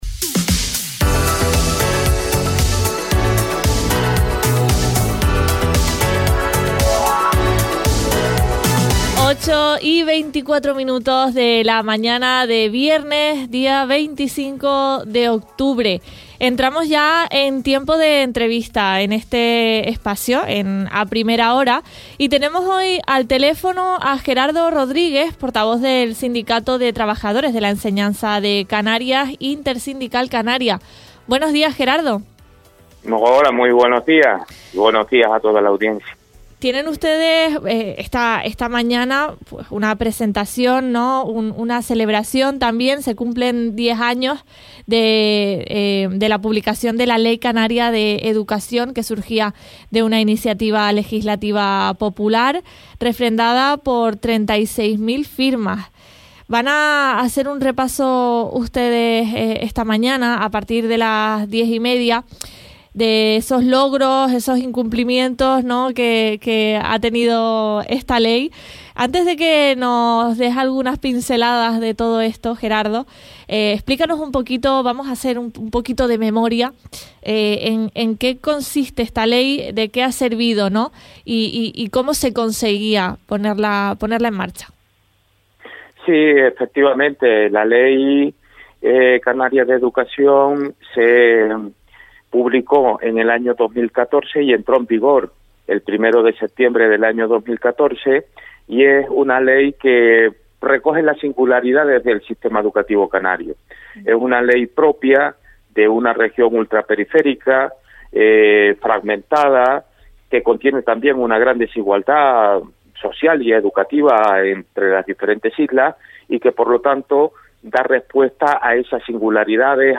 Entrevistas A Primera Hora